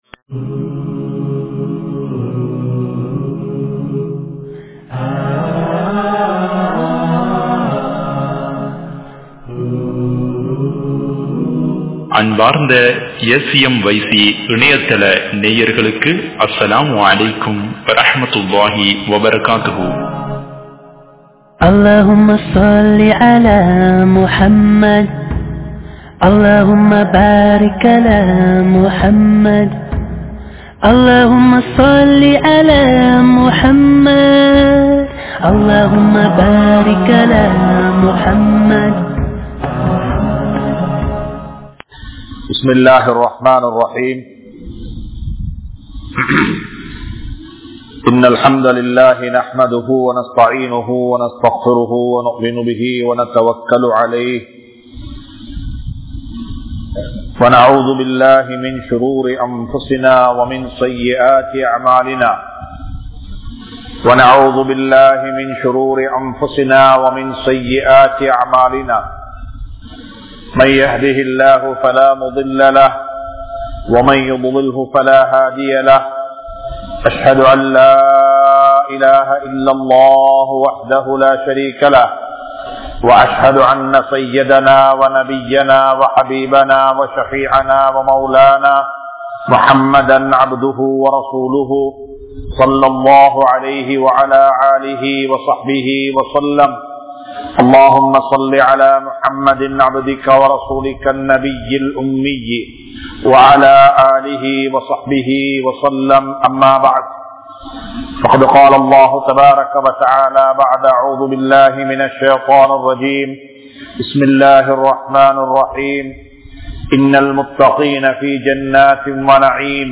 Suvarkam(Part 02) (சுவர்க்கம் (பகுதி 02) | Audio Bayans | All Ceylon Muslim Youth Community | Addalaichenai
Muhideen (Markaz) Jumua Masjith